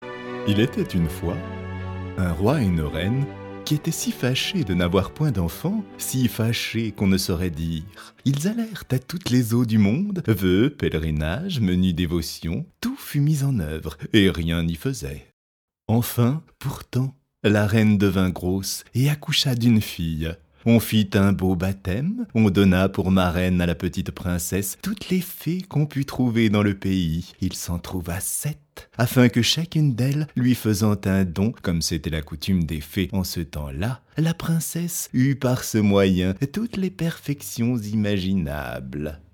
Diffusion distribution ebook et livre audio - Catalogue livres numériques
Durée : 26 minutes Illustration musicale : Canon de Pachelbel 2 , 00 € Ce livre est accessible aux handicaps Voir les informations d'accessibilité